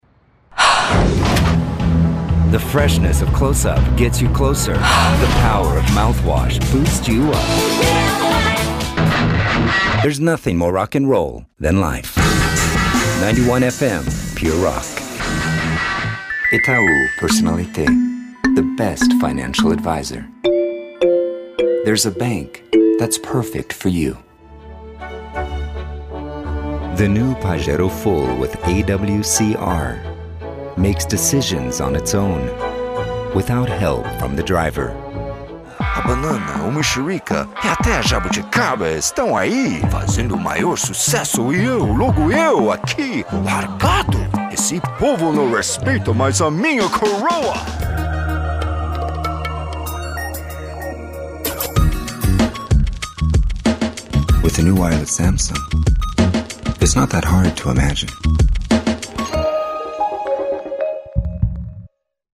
Friendly, honest, casual, American voice
Sprechprobe: Werbung (Muttersprache):